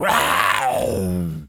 pgs/Assets/Audio/Animal_Impersonations/tas_devil_cartoon_04.wav at master
tas_devil_cartoon_04.wav